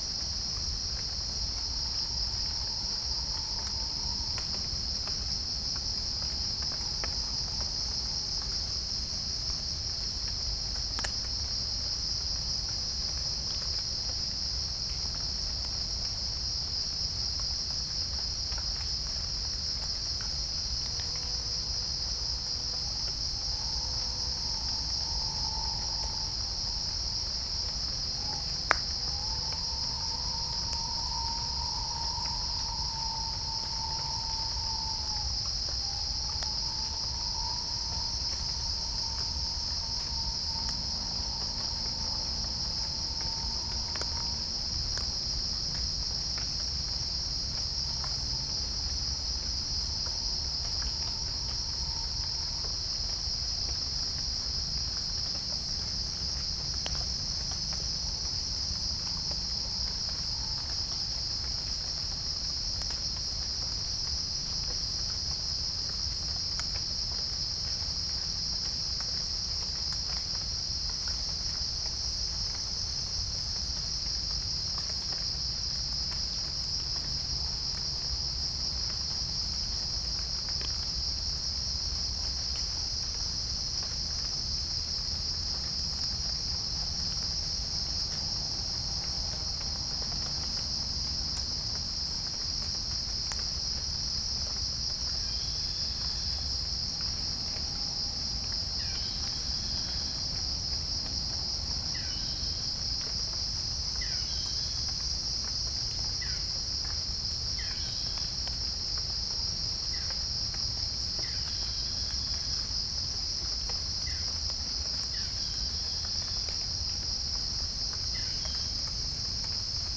Geopelia striata
Pycnonotus goiavier
Halcyon smyrnensis
Orthotomus ruficeps
Dicaeum trigonostigma